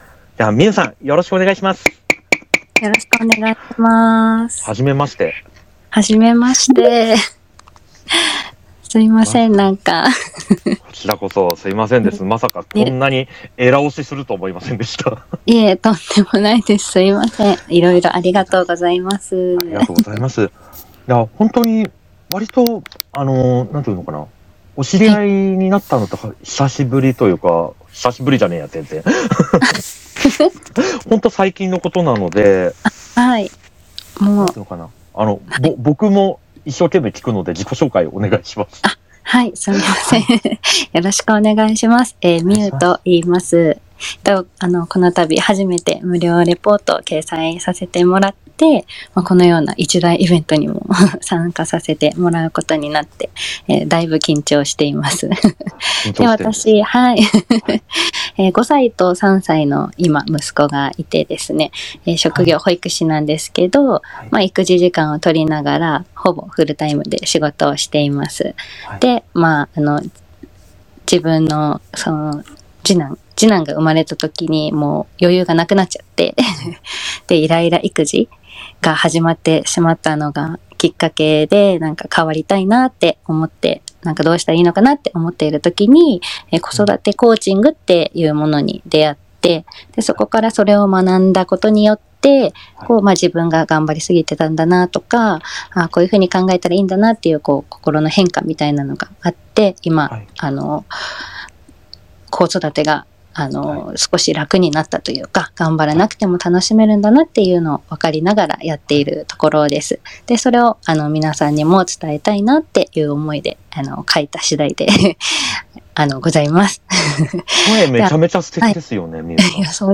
自分のレポートをPRするために、３月１４日に開催された「第31回e-Book大賞参加者応援Twitterスペース祭」というイベントにも参加しました。
自分の出番の部分だけアーカイブを音声化しましたので、もし良かったら聞いてみてください(^^)